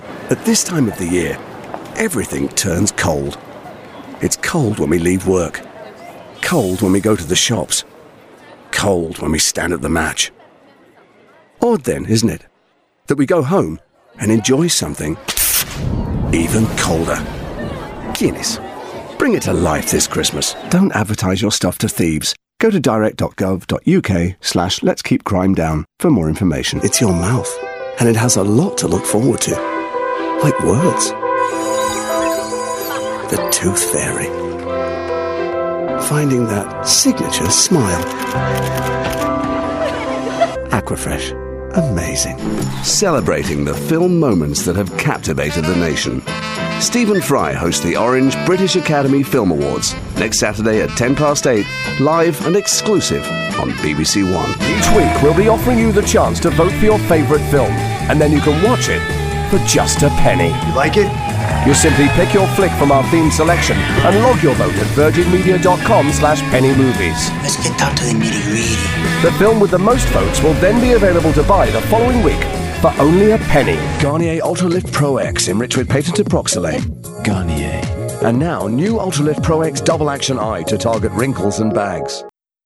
Commercial Showreel
Showreel